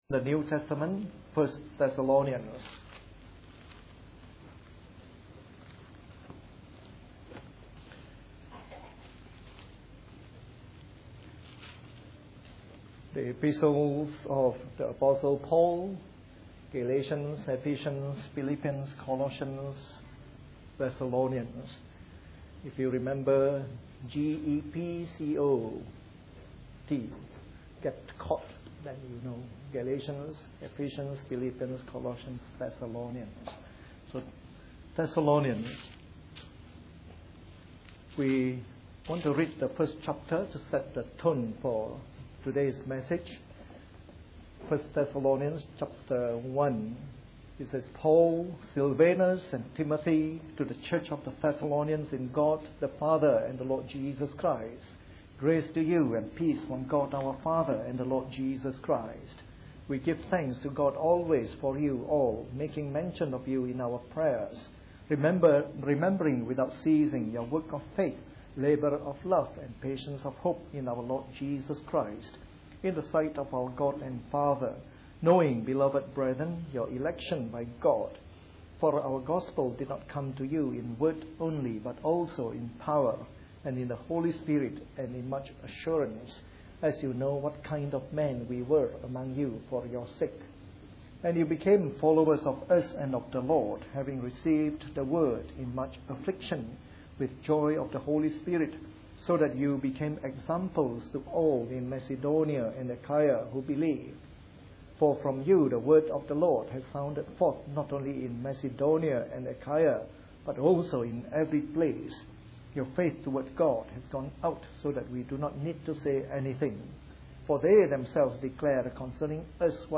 A sermon in the morning service from our new series on 1 Thessalonians.